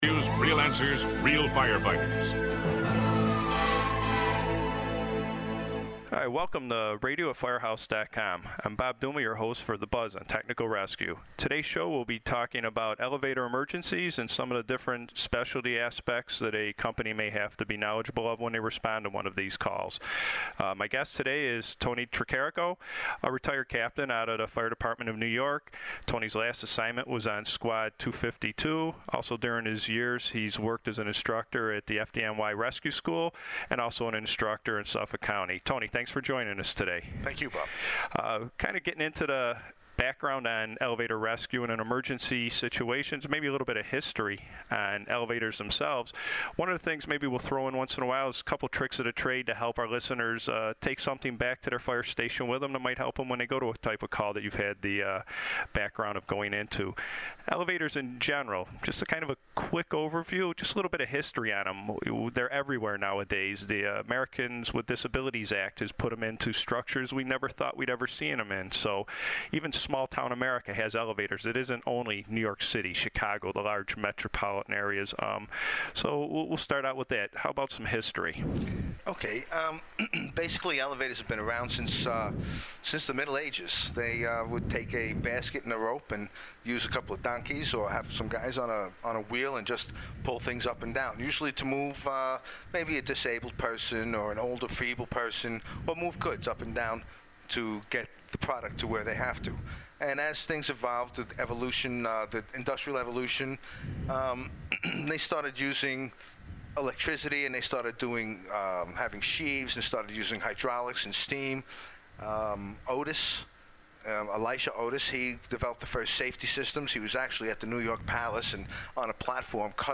They talk through elevator emergency situation size-ups, utility controls and elevator access points.